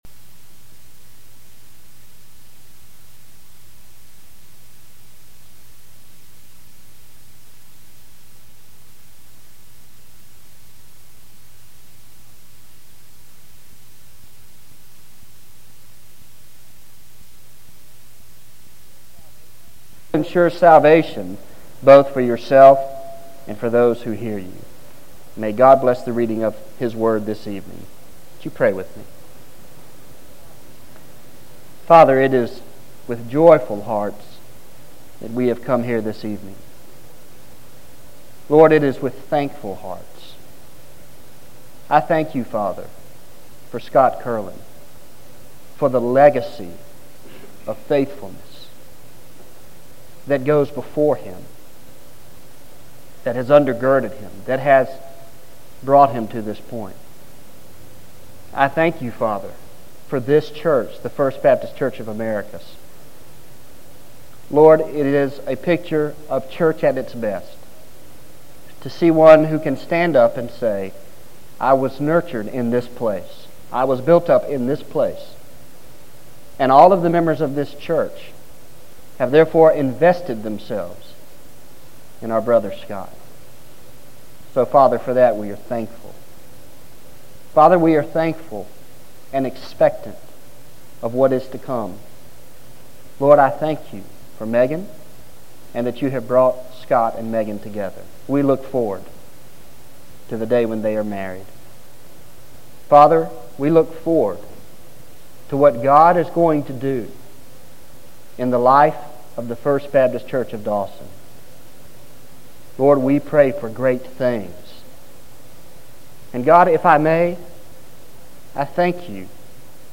1 Timothy 4:12-16 (Preached on August 13, 2006, at First Baptist Church, Americus, GA